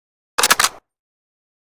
kar98k_reload_start.ogg